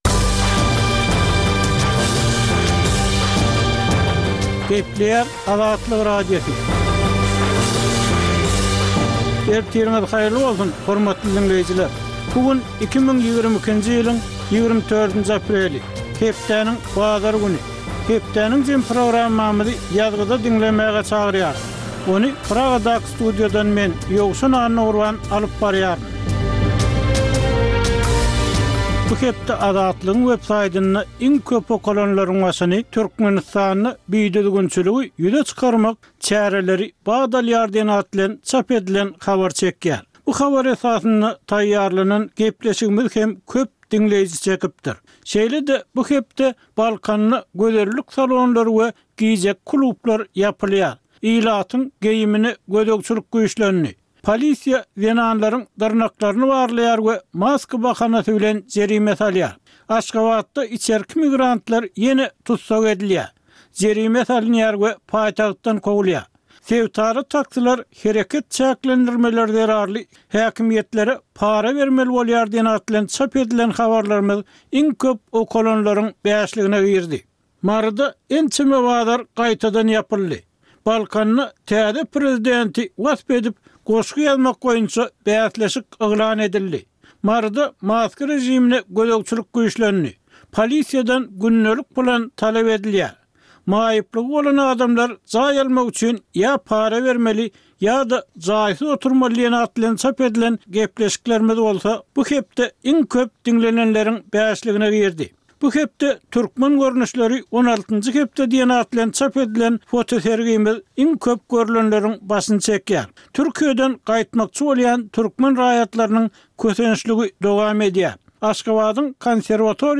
Tutuş geçen bir hepdäniň dowamynda Türkmenistanda we halkara arenasynda bolup geçen möhüm wakalara syn. Bu ýörite programmanyň dowamynda hepdäniň möhüm wakalary barada synlar, analizler, söhbetdeşlikler we kommentariýalar berilýär.